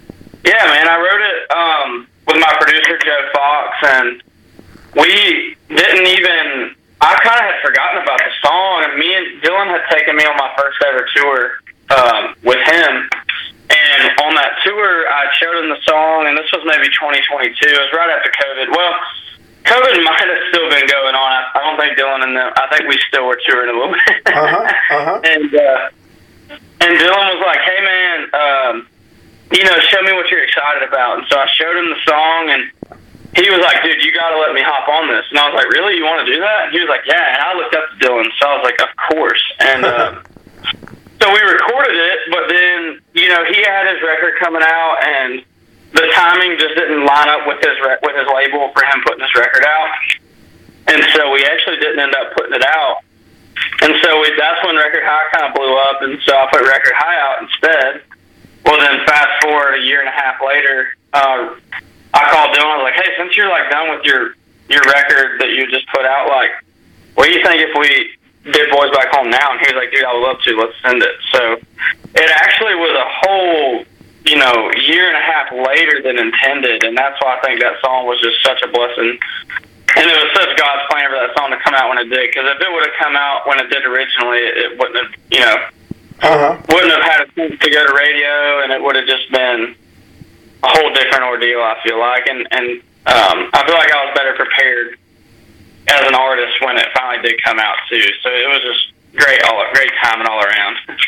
Interview with rising country artist Dylan Marlowe, about his hit "Boys Back Home," his album Mid-Twenties Crisis and his songs.